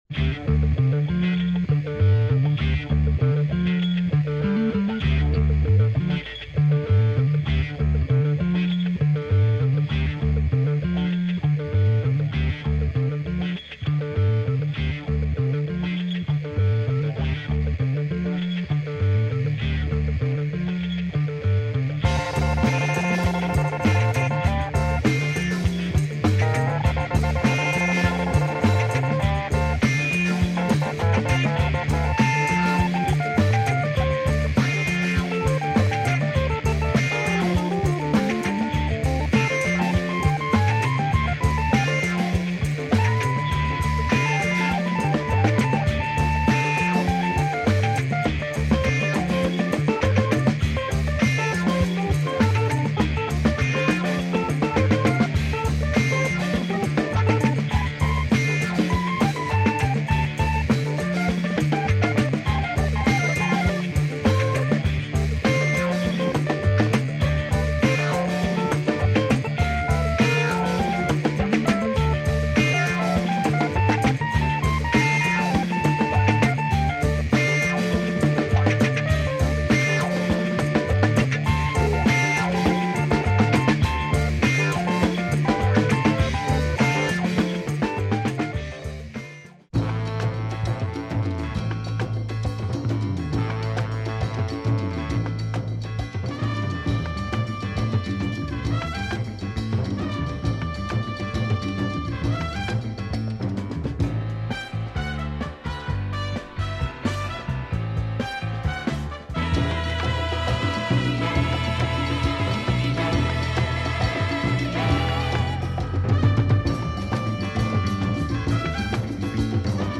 Killer organ funk